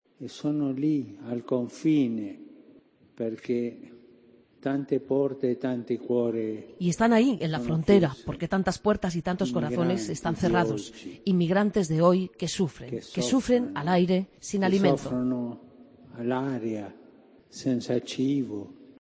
El Papa Francisco en la Audiencia de este miércoles recuerda a los refugiados: "Inmigrantes de hoy que sufren al aire sin alimento"